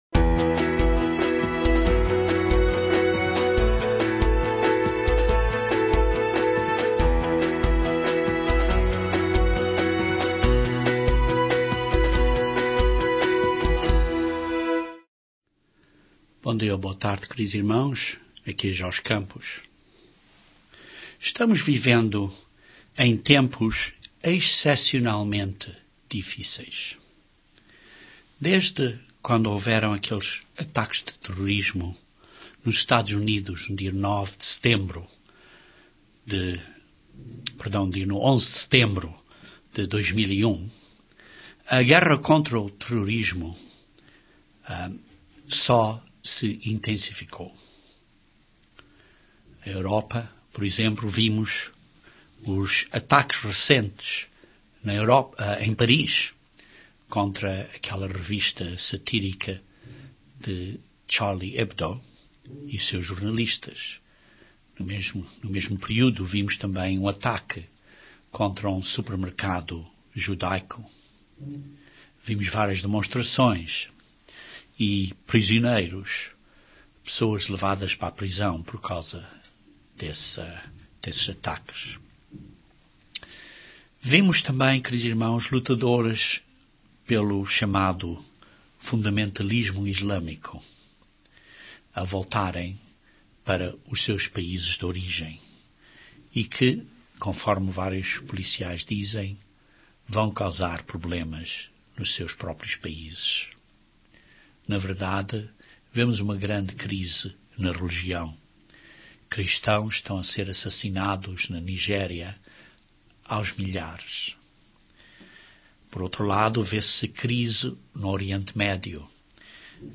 Este sermão descreve alguns dos sinais identificadores para que não sejamos enganados.